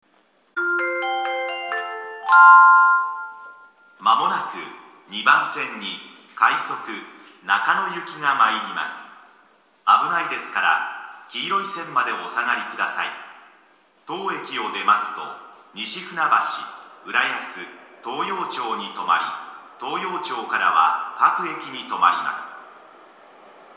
駅放送